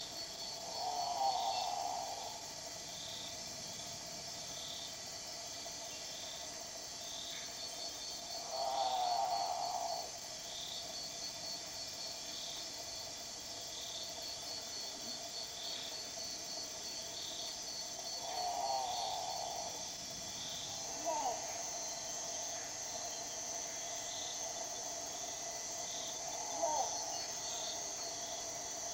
الپوتو الكبير